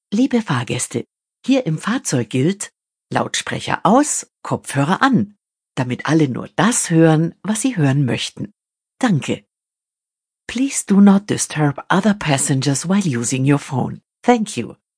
Neue Ansage in den Fahrzeugen für mehr Rücksichtnahme